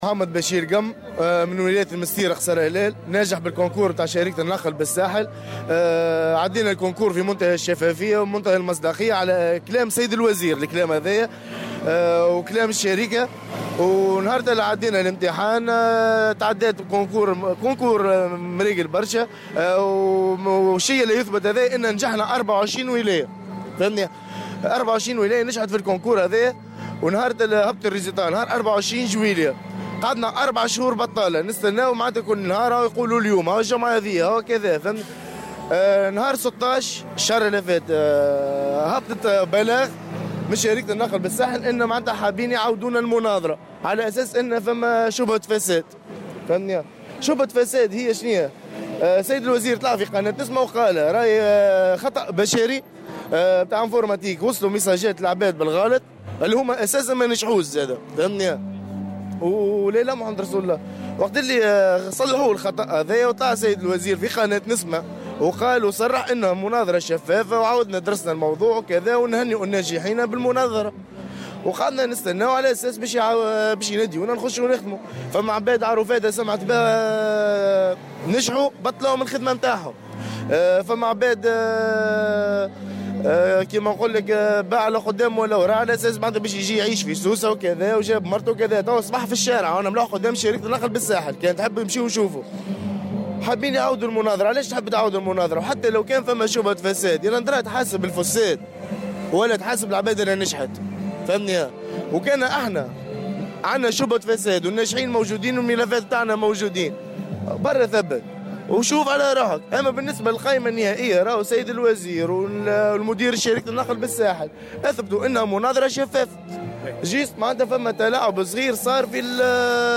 أحد المحتجين تحميل المشاركة علي مقالات أخرى وطنية 24/09/2025 اليوم: الأمطار تتواصل بهذه المناطق..